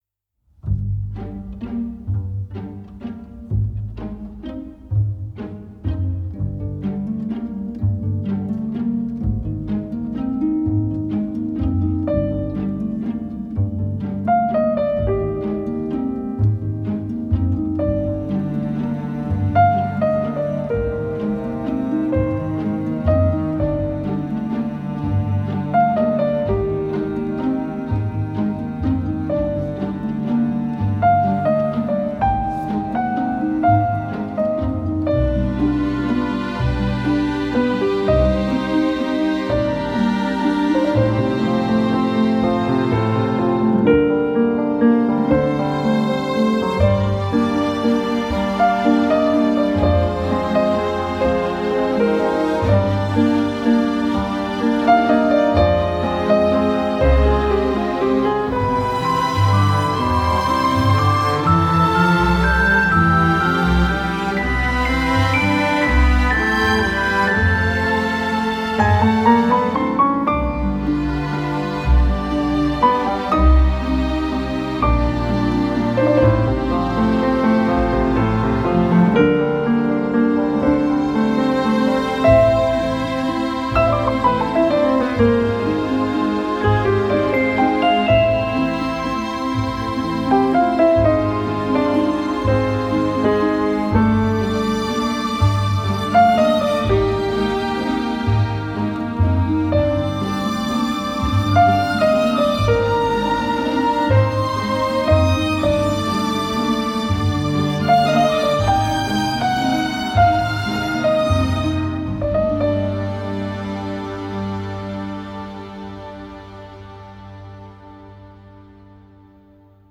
banda sonora